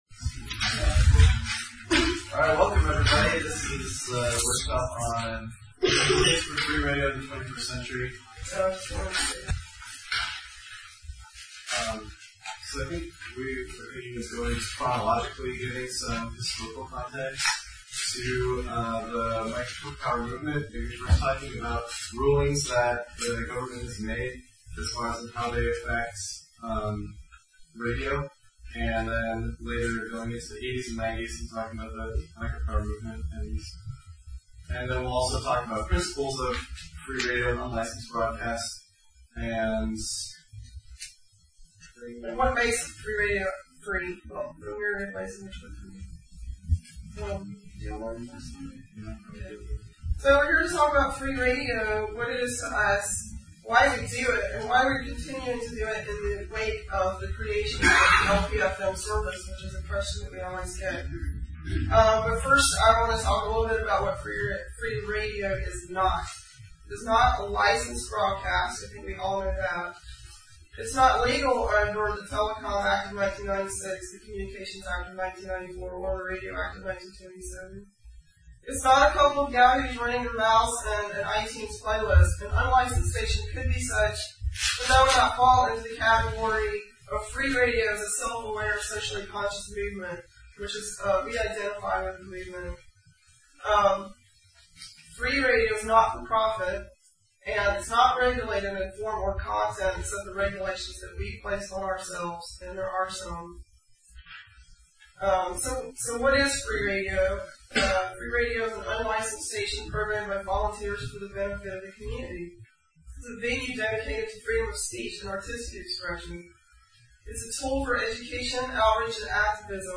Just two weeks before the FCC's visit, RtM also organized the Northwest Community Radio Summit , which featured three days of workshops on a wide range of issues. One of those was on " The Case for Free Radio in the 21st Century " (1:00:34, 10.4 MB), hosted by members of the Free Radio Olympia collective. It provided a short overview of the history of unlicensed broadcasting and some of the more popular rationales for why it's still advantageous to be a radio pirate in a post-LPFM world.